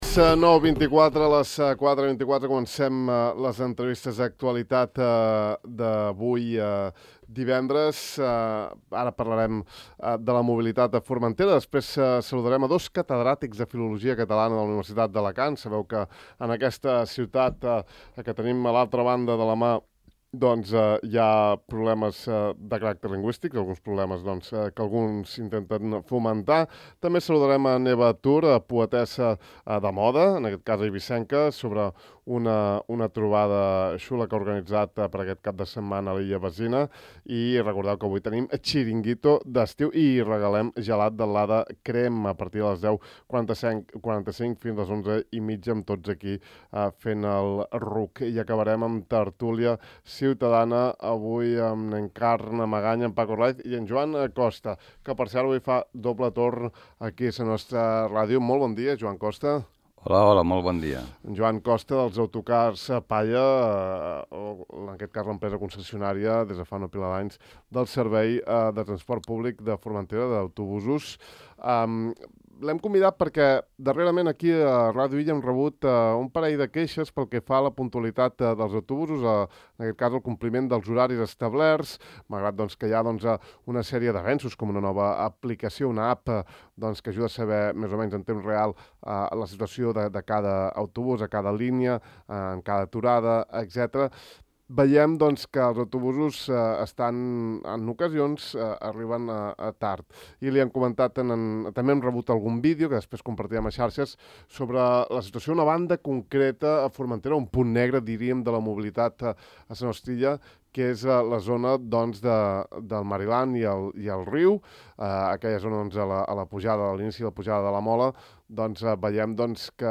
En l’entrevista